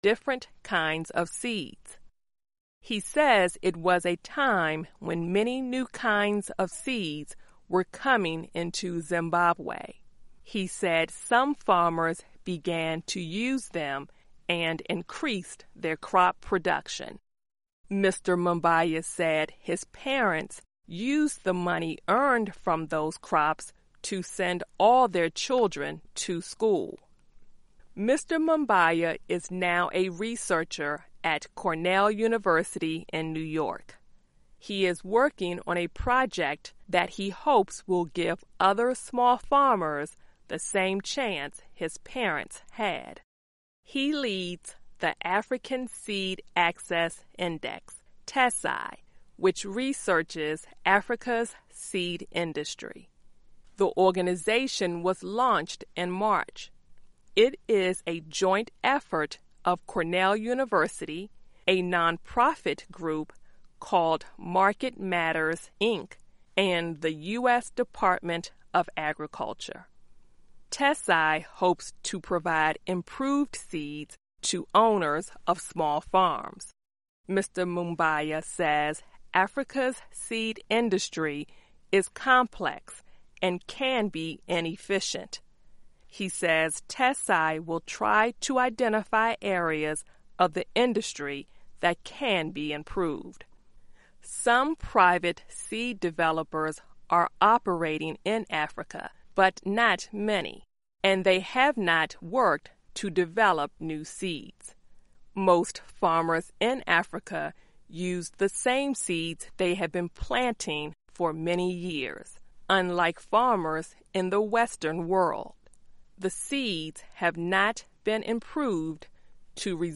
Learning English as you listen to a weekly show about the environment, science, farming, food security, gardening and other subjects. Our daily stories are written at the intermediate and upper-beginner level and are read one-third slower than regular VOA English.